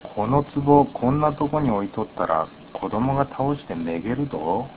下関弁辞典
発音